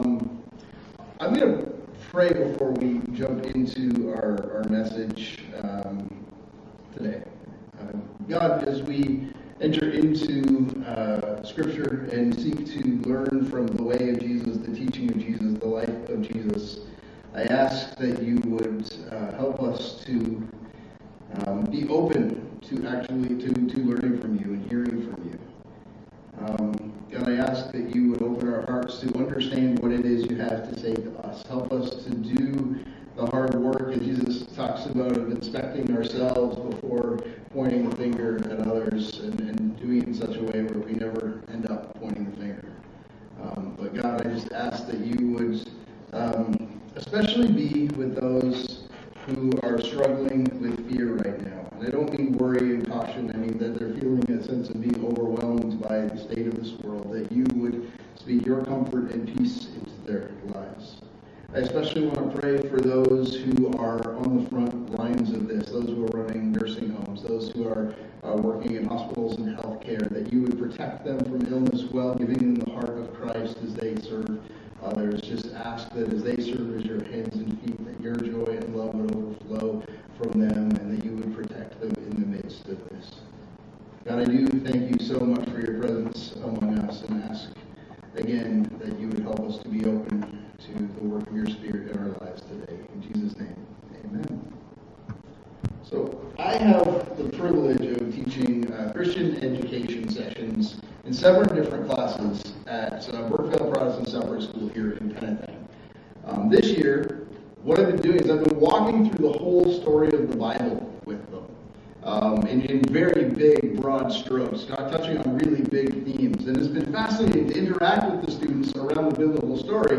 Sermons | Covenant Christian Community Church